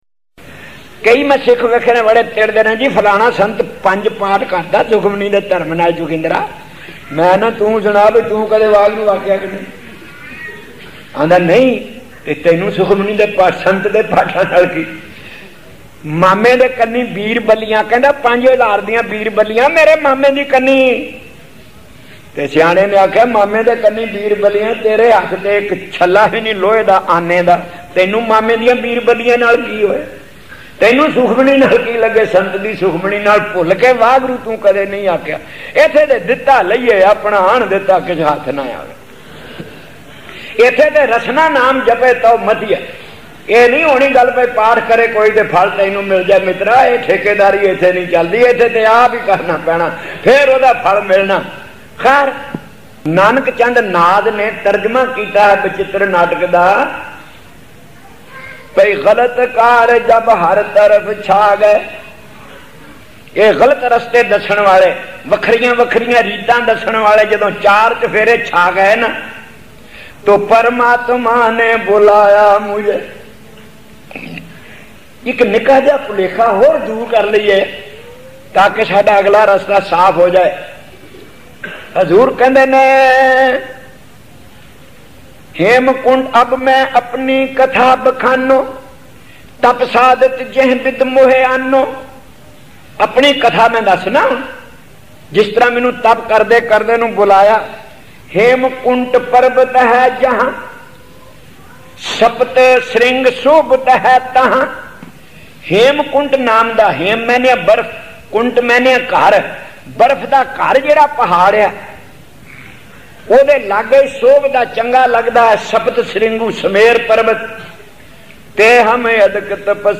Kuala Lumpur, 1970.